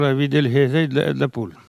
Maraîchin
Locution